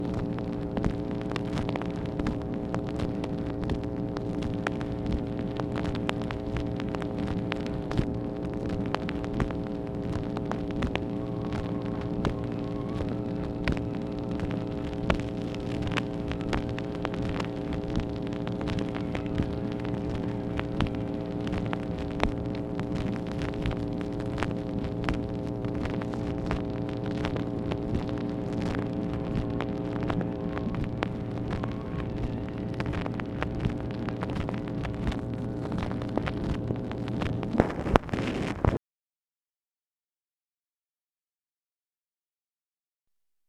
OFFICE NOISE, August 25, 1964
Secret White House Tapes | Lyndon B. Johnson Presidency